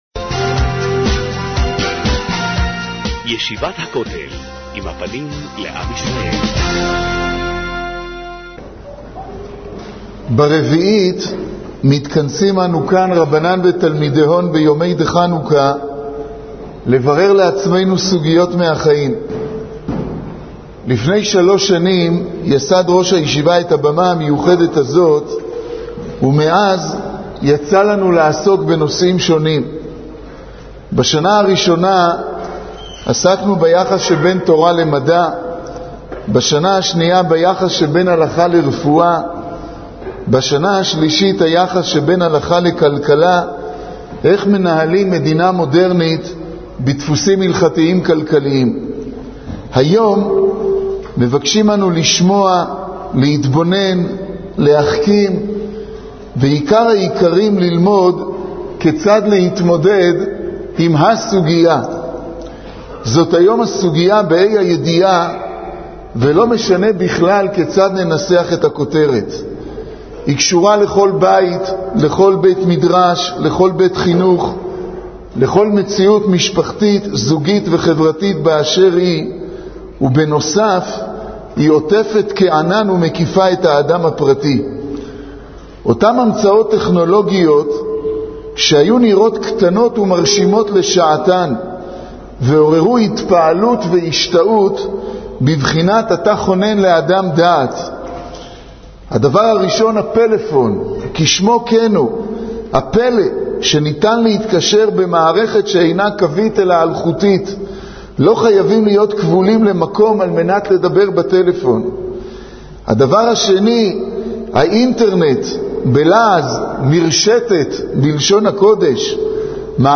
שיחת פתיחה ליום העיון